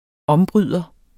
Udtale [ -ˌbʁyðˀʌ ]